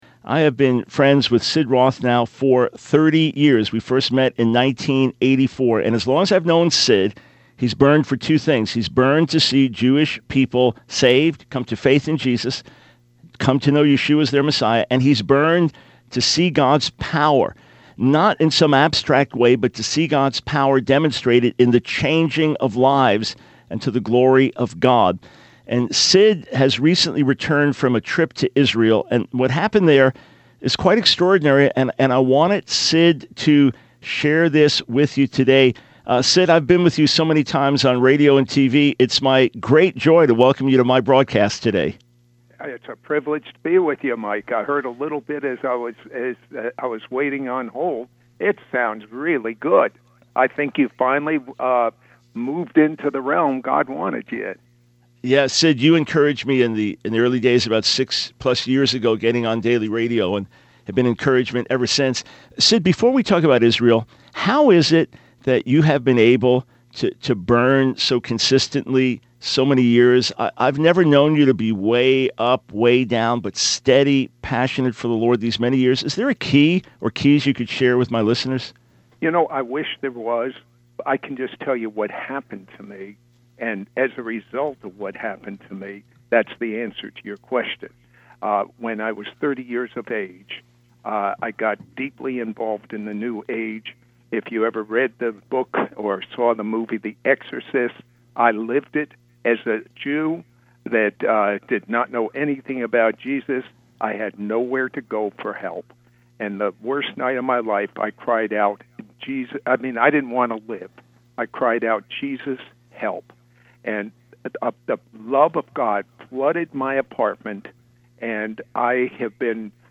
Dr. Michael Brown Interviews Sid Roth - Sid Roth – It’s Supernatural!
In the interview, Dr. Brown speaks with Sid about our recent historic evangelistic outreach in Israel.